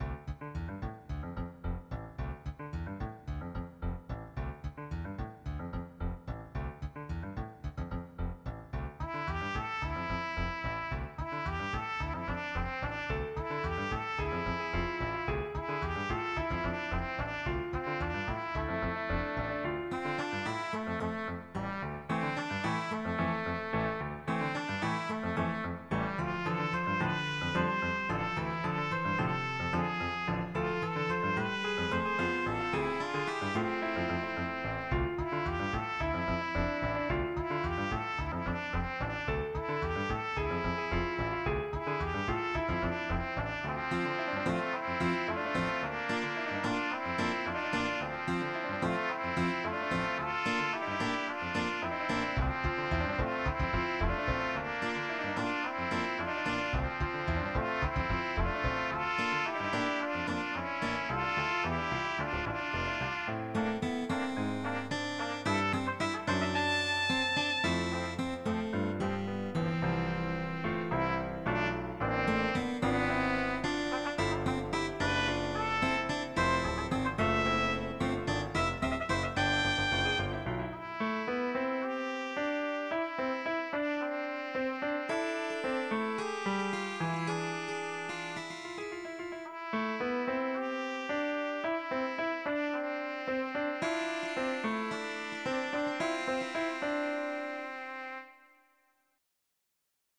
Did you hear the haunting melody quoted there
from the trumpet player